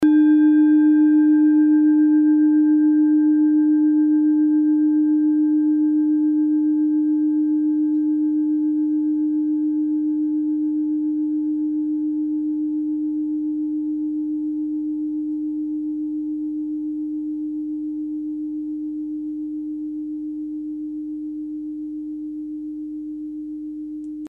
Klangschale Bengalen Nr.11
Klangschale-Gewicht: 710g
Klangschale-Durchmesser: 15,5cm
Die Klangschale kommt aus einer Schmiede in Bengalen (Ostindien). Sie ist neu und wurde gezielt nach altem 7-Metalle-Rezept in Handarbeit gezogen und gehämmert.
Eros-Ton: